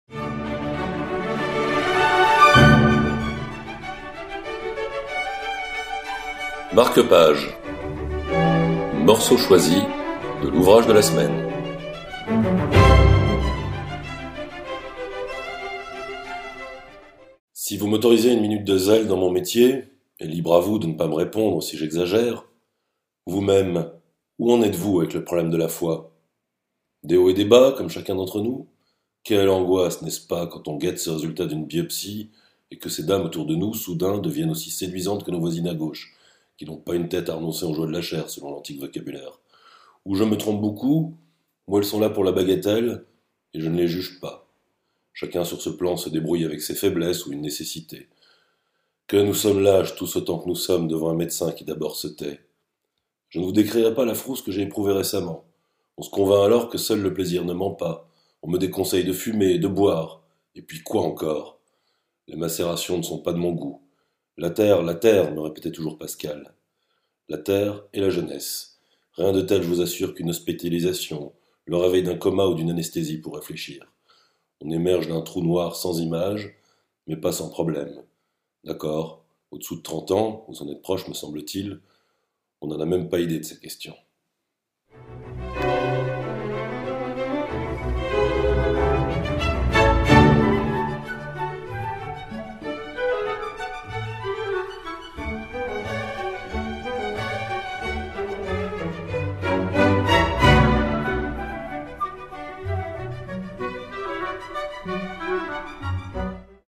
Extrait lu de Torrent, d’Angelo Rinaldi (Fayard 2016).